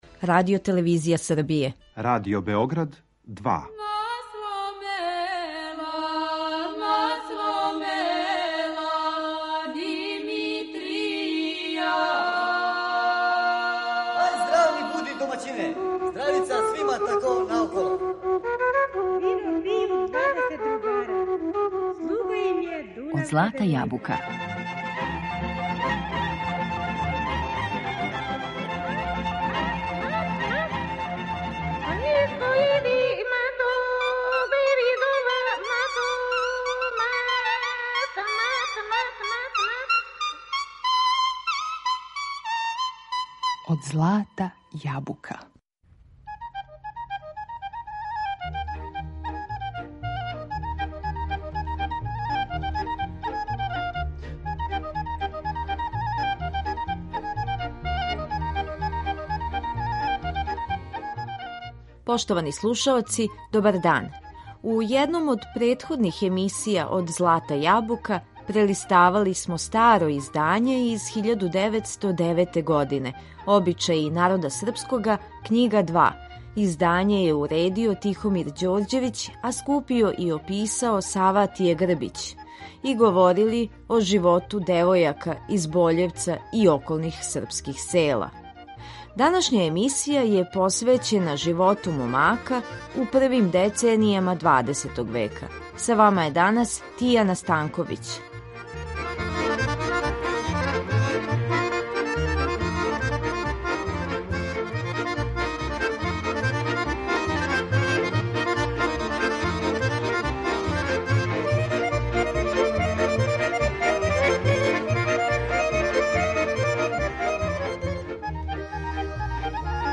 На репертоару су пригодне песме из различитих крајева наше земље.